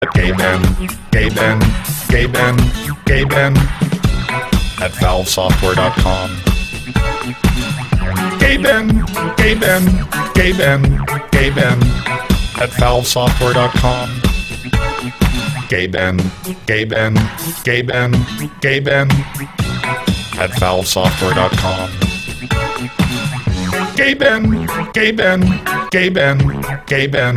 Electronic
Bass
электронный голос